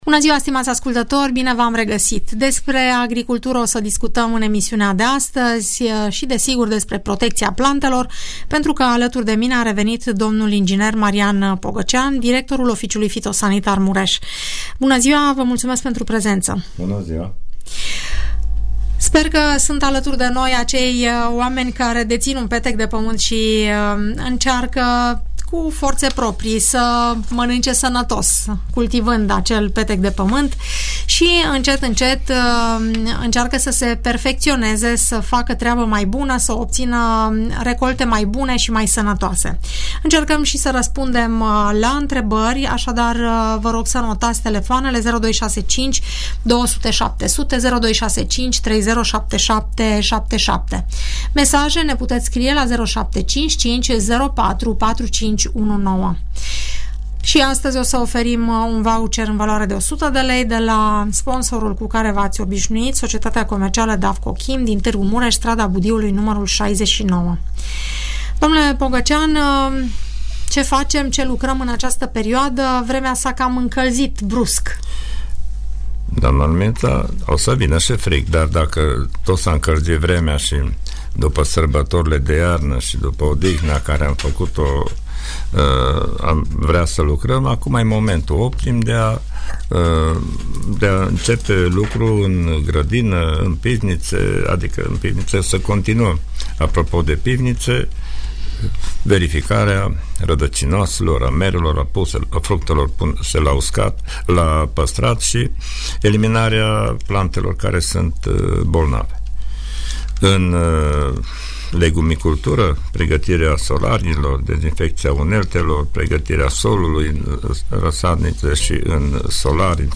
răspunde în direct la întrebările ascultătorilor care cultivă pământul.